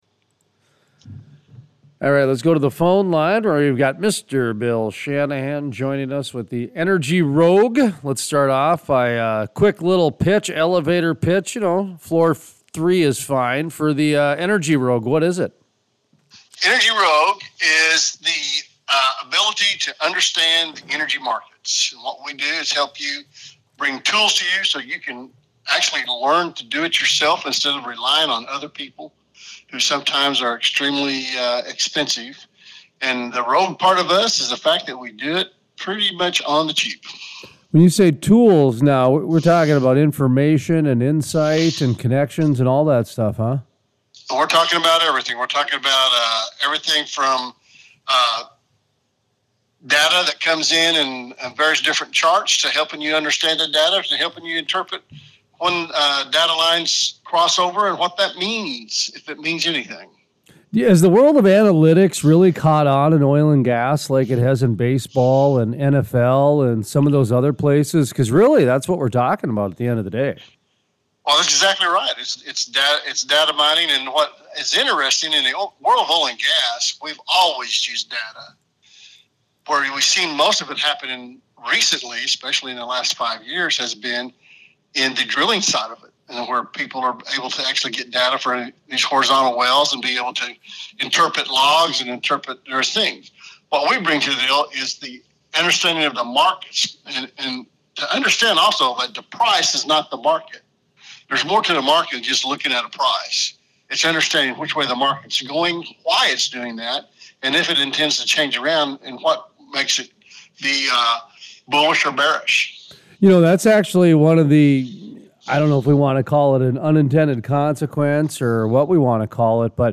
Full Length Interviews